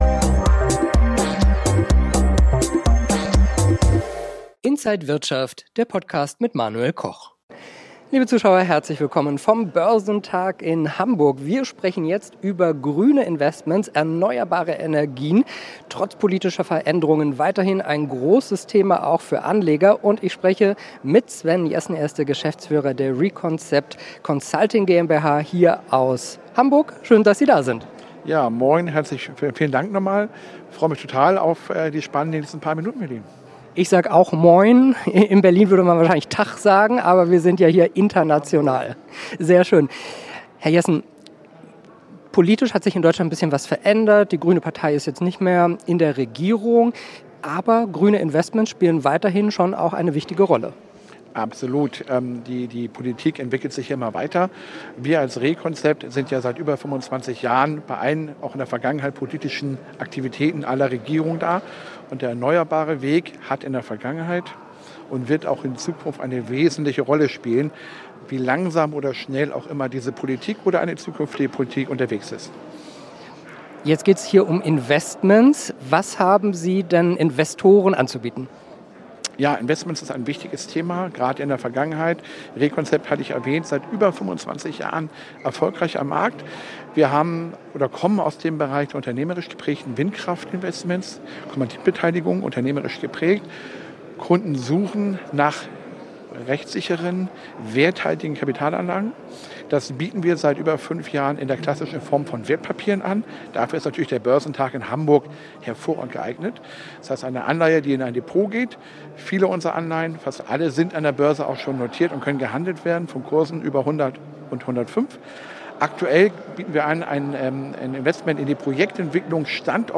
Alle Details im Interview von Inside
auf dem Börsentag Hamburg